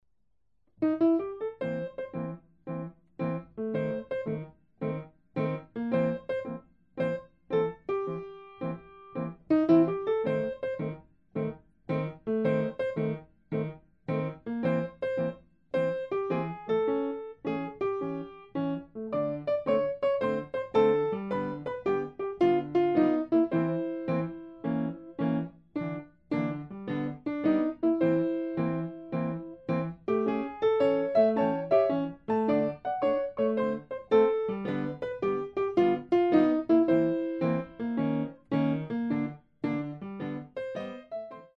Style: Trad. Jazz Piano